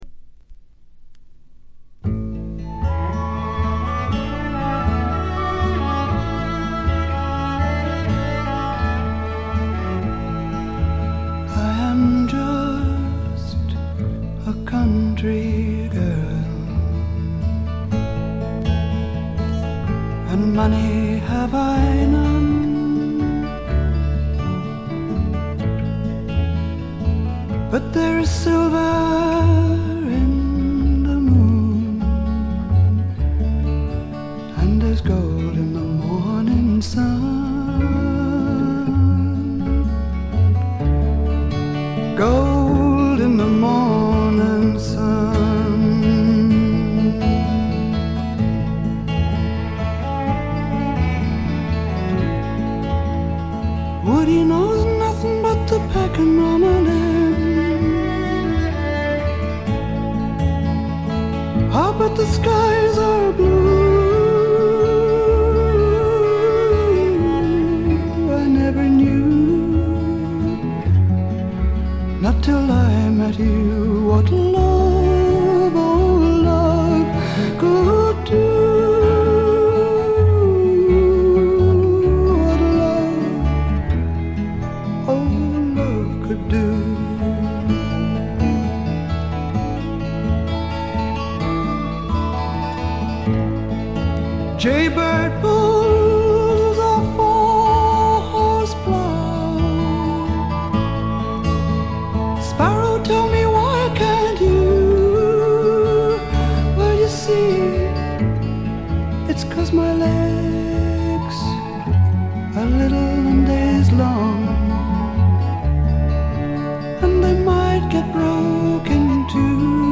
実に魅力的なアルト・ヴォイスの持ち主で、初めて聴く方はまずはこの声にやられてしまうことでしょう。
この強力な声を武器にシンプルなバックを従えて、フォーキーでジャジーでアシッドでダークなフォークロックを展開しています。
試聴曲は現品からの取り込み音源です。